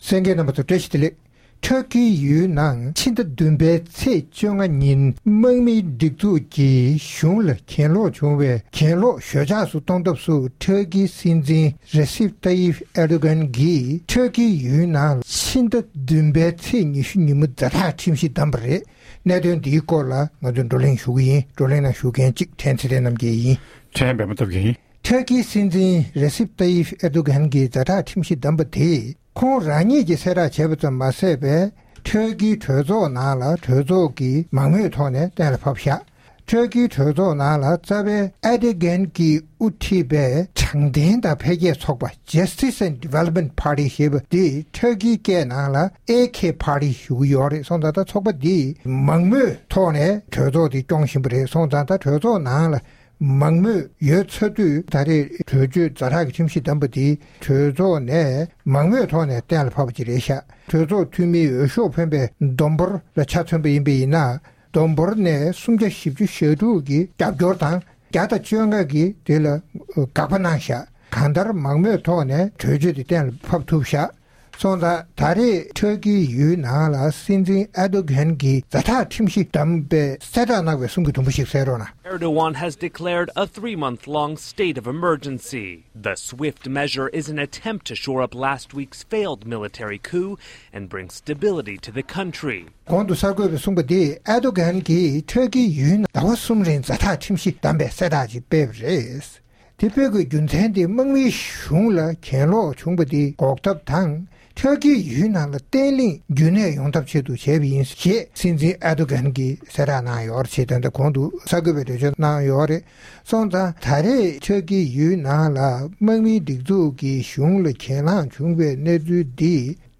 ༄༅༎ཐེངས་འདིའི་རྩོམ་སྒྲིག་པའི་གླེང་སྟེགས་ཞེས་པའི་ལེ་ཚན་ནང་། Turkeyཡུལ་ནང་དམག་མིས་གཞུང་ལ་གྱེན་ལོག་བྱས་ཀྱང་གཞུང་གིས་གྱེན་ལངས་ཞོད་འཇགས་སུ་བཏང་བ་མ་ཟད། ལེ་ཐོགས་ཡོད་པའི་མི་སྣ་དང་གཞན་ཡང་ཕྱོགས་འགལ་ཚོགས་པའི་མཐོ་མཐའ་བར་གསུམ་གྱི་མི་སྣ་ཁྲི་ཚོ་མང་པོ་འཛིན་བཟུང་བཙོན་འཇུགདང་། ཛ་དྲག་ཁྲིམས་གཞི་བདམས་པ་སོགས་ཀྱི་སྐོར་རྩོམ་སྒྲིག་འགན་འཛིན་རྣམ་པས་བགྲོ་གླེང་གནང་བ་ཞིག་གསན་རོགས་གནང་།།